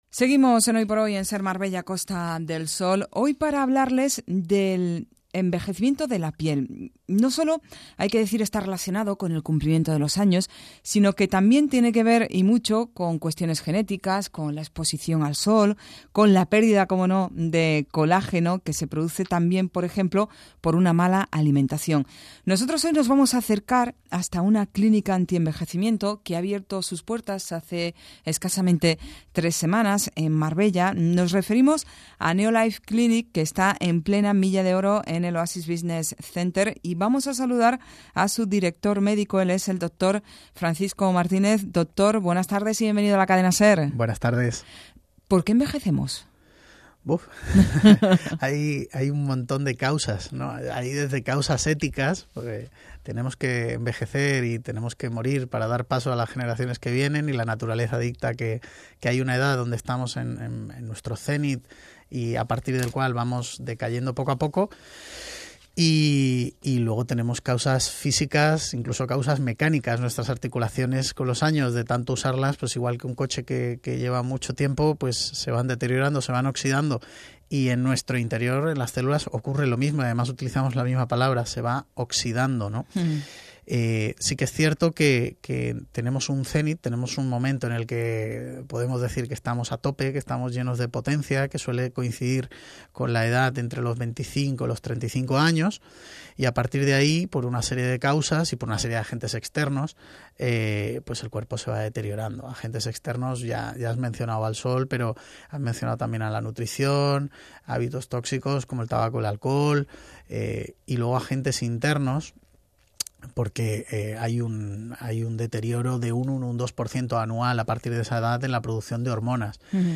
fue invitado a la cadena Ser (Ser Marbella Costa del Sol).